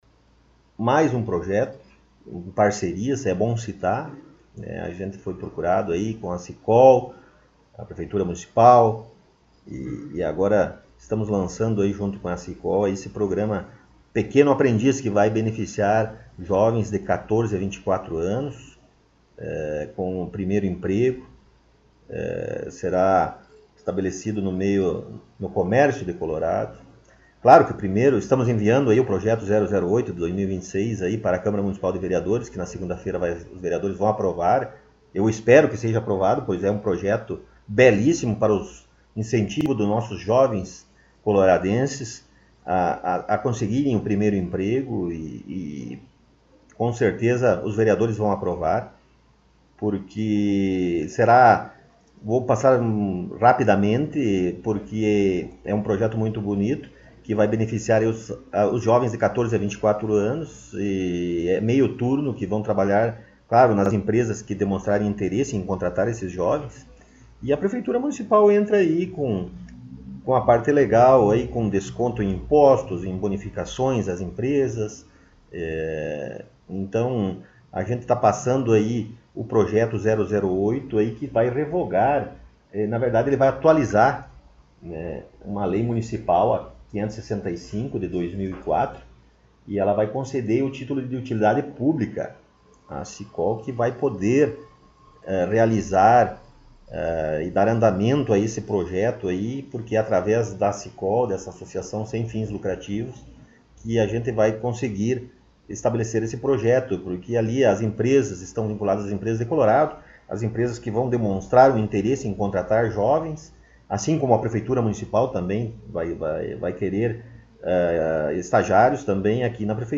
Prefeito Rodrigo Sartori concedeu entrevista
Na última semana, mais uma vez, o jornal Colorado em Foco teve a oportunidade de entrevistar o prefeito Rodrigo Sartori em seu gabinete na Prefeitura Municipal.